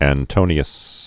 (ăn-tōnē-əs), Marcus